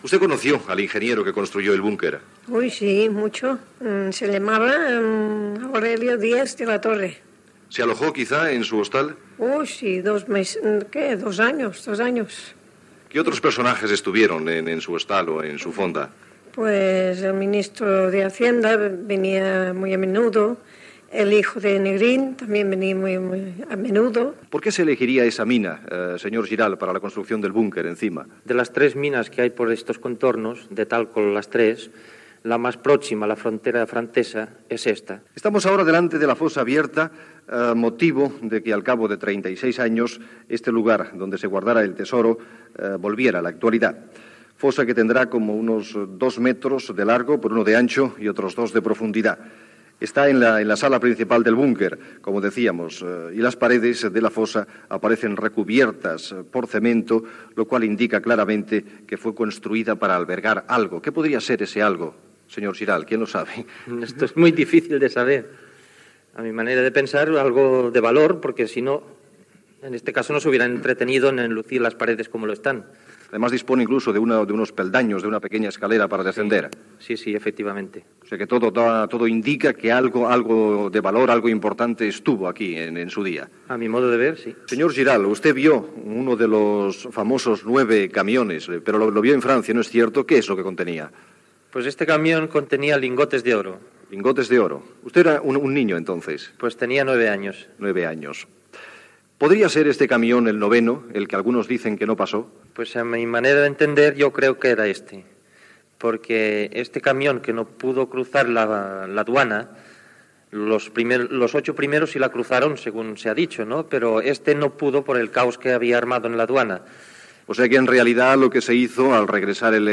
Reportatge sobre el búnquer construït l'any 1938 en una mina per amagar el tressor de Negrín a La Vajol (Alt Empordà)